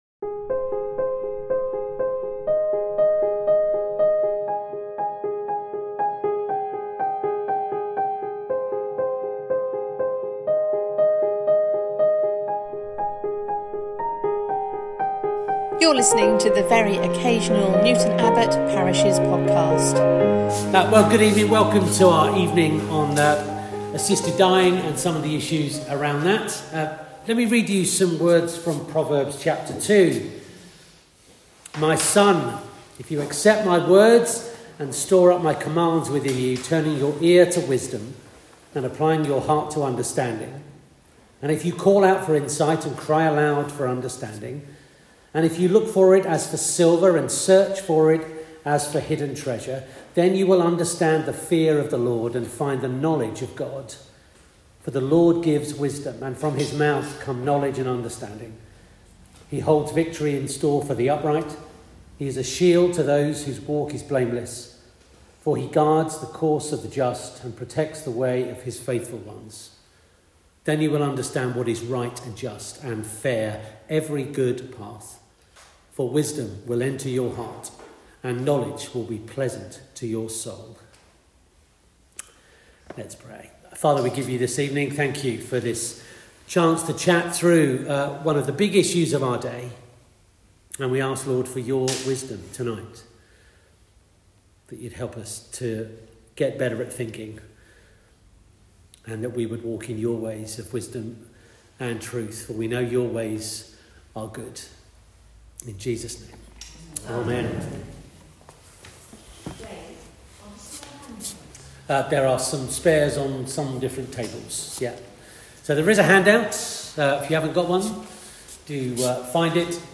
This talk was delivered in three parts at St. Paul’s, Newton Abbot on Sunday 2nd March